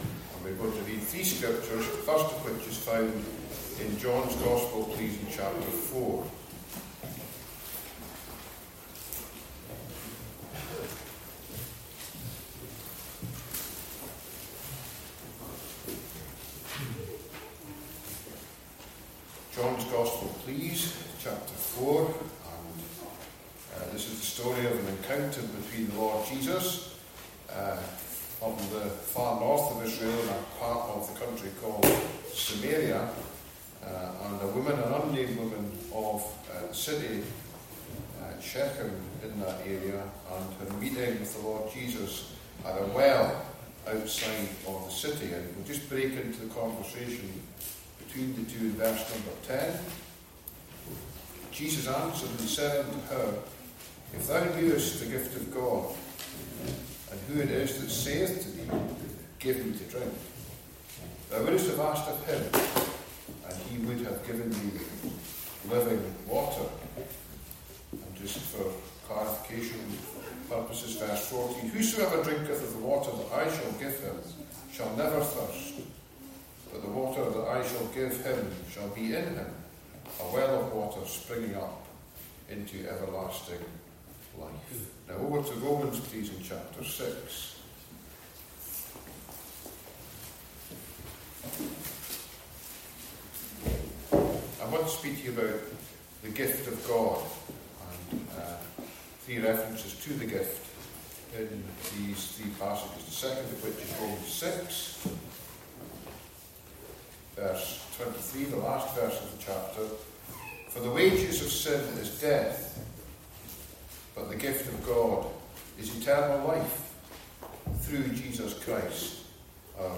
Various Gospel Messages